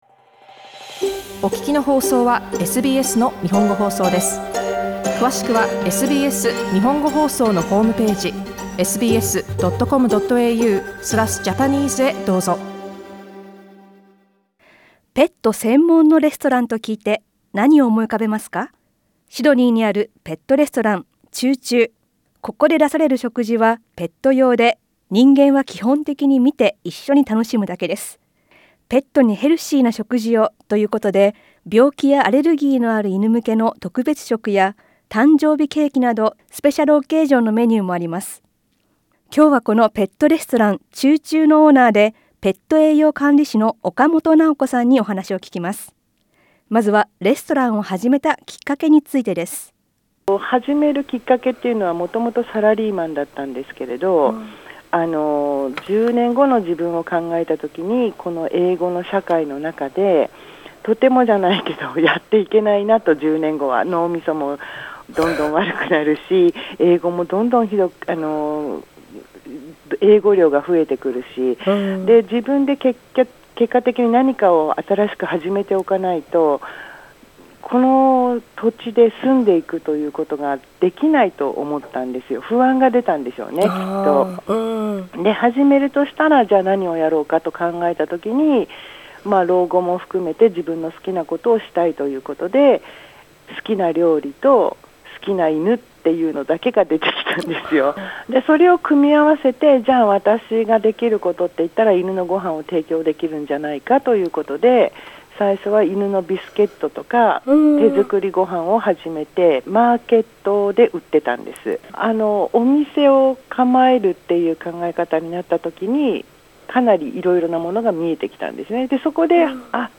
インタビューでは、ペットレストランで出されている食事や事業について、また会社員を辞めて企業したきっかけについて聞きました。